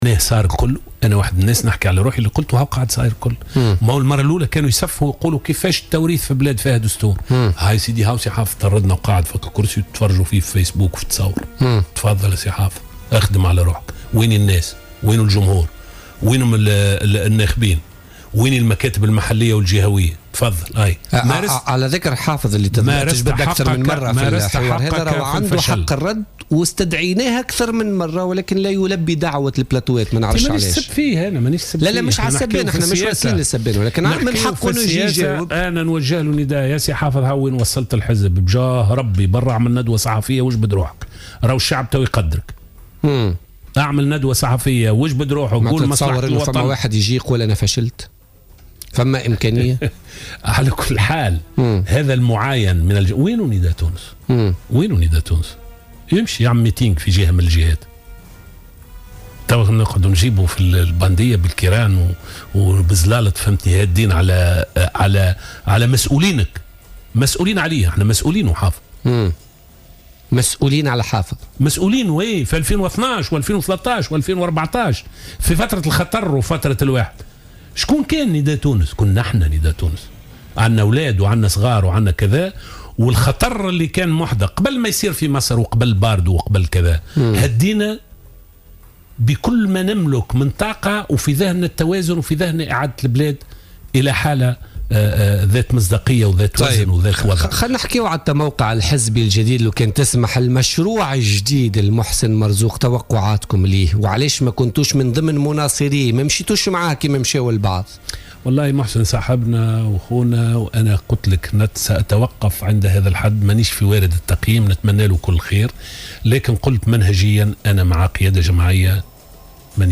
قال القيادي السابق في نداء تونس لزهر العكرمي ضيف بوليتيكا اليوم الأربعاء 16 مارس 2016 أن كان يتمنى دائما أن تتونس وتكون حزب مدني حركة النهضة لتصبح حزبا نفتخر به أمام الدول التي فيها أحزاب متطرفة.